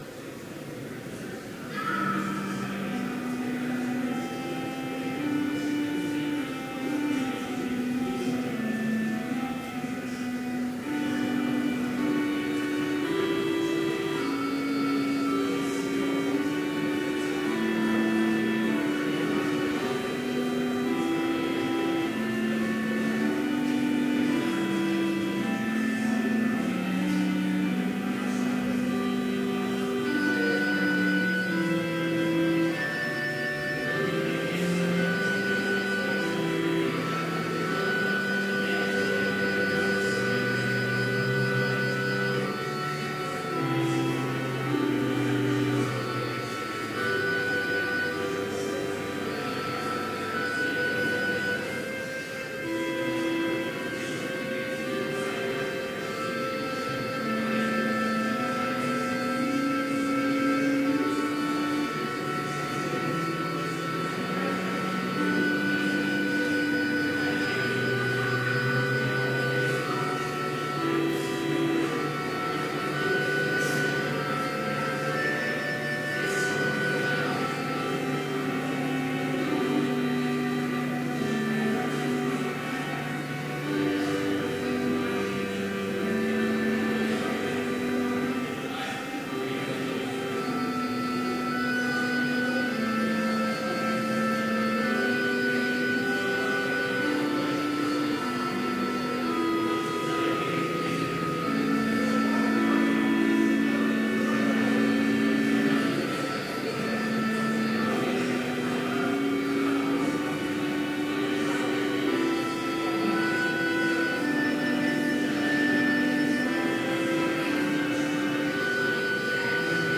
Complete service audio for Chapel - September 14, 2017